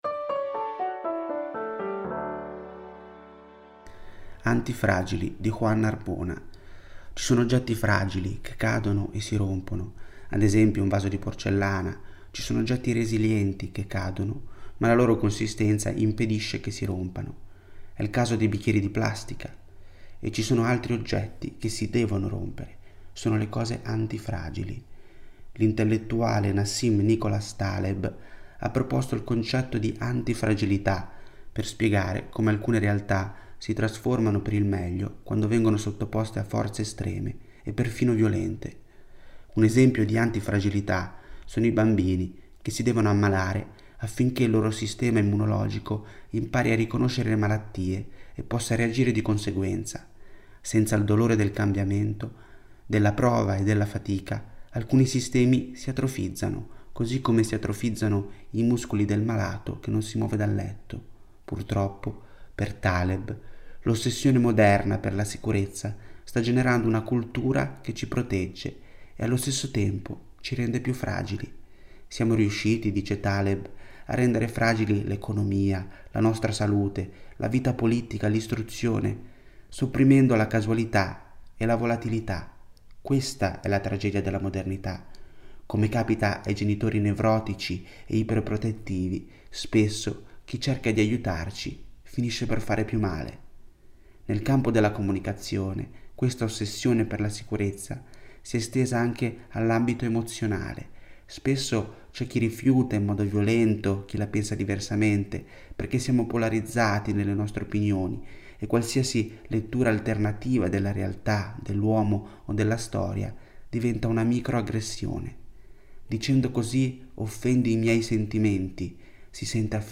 Al microfono, i nostri redattori e i nostri collaboratori.
Ecco i 10 articoli letti per voi dalla rivista di novembre.